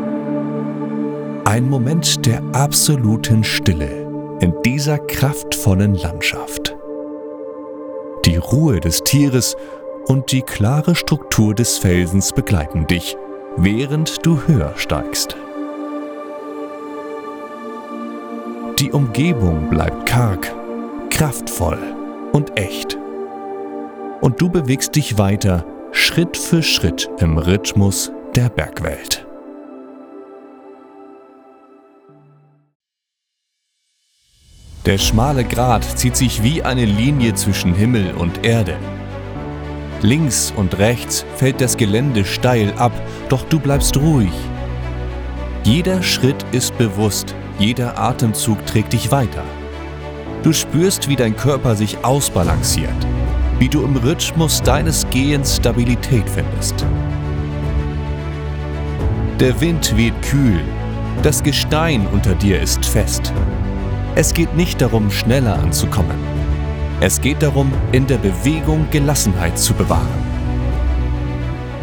Drei exklusive Audioreisen Professionell eingesprochen, mit Musik hinterlegt und inspiriert von Mentaltraining (Dauer zw. 15 und 25 Minuten).